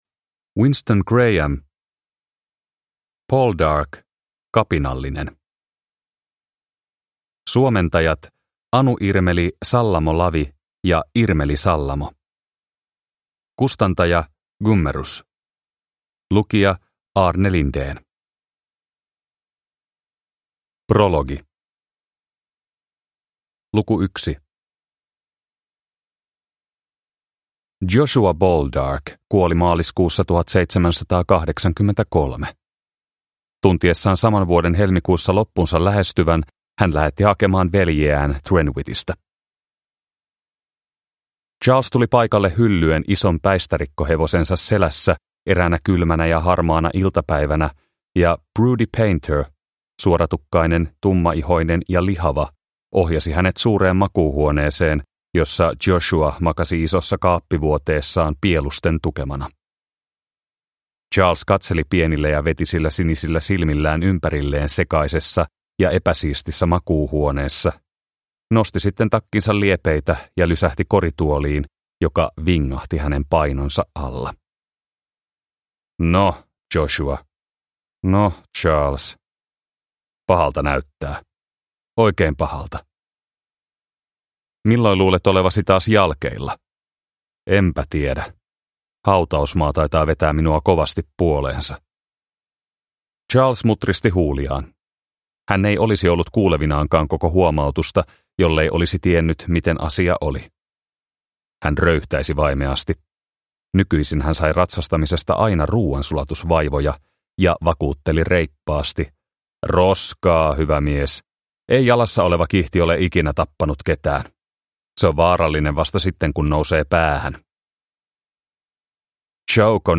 Poldark - Kapinallinen (ljudbok) av Winston Graham